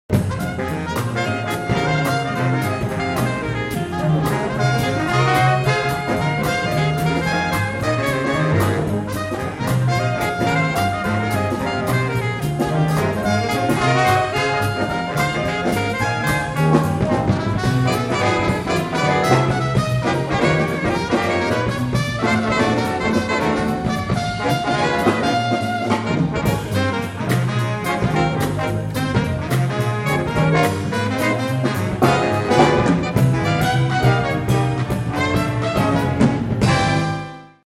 Jazz Nonet is a form which really captivates me.
Haleakala (House of the Sun) = Fast rhythm changes in nonet style.